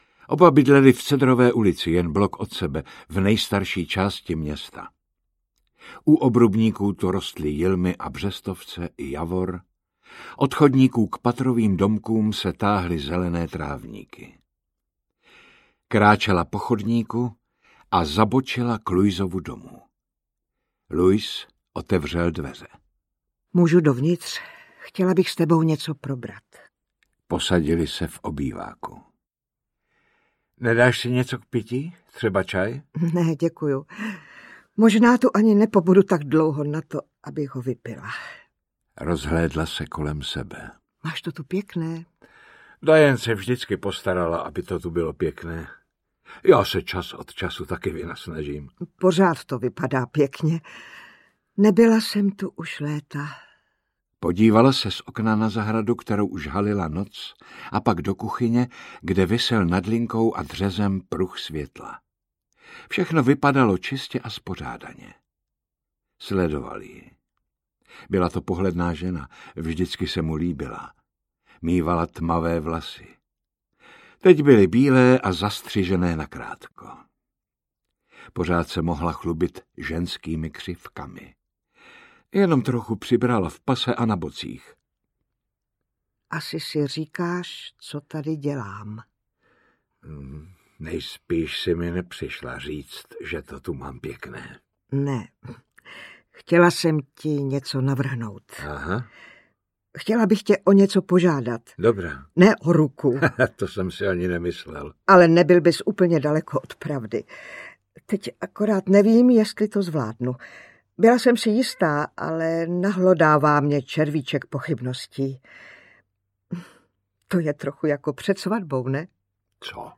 Cizinci v noci audiokniha
Ukázka z knihy
• InterpretDana Syslová, Jaromír Meduna